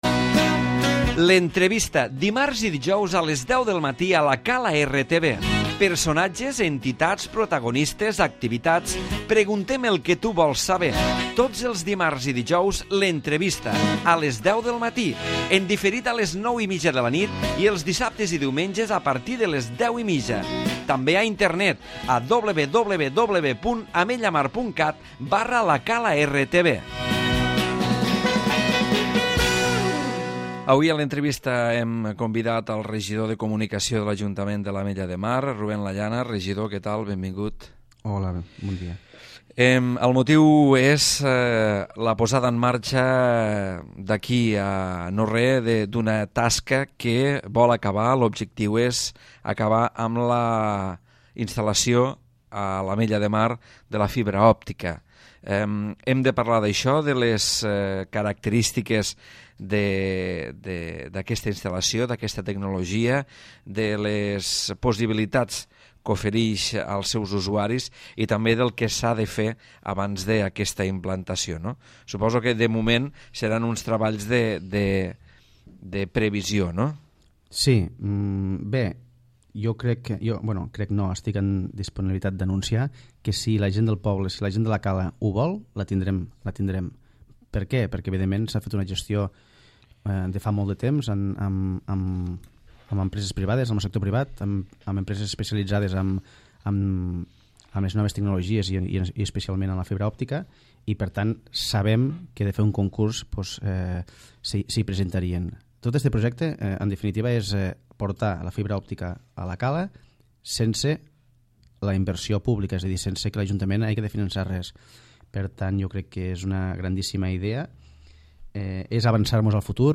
L'entrevista
Ruben Lallana, regidor de Comunicació, ens parla de l'arribada de la fibra òptica.